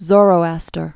(zôrō-ăstər) or Zar·a·thu·stra (zărə-thstrə)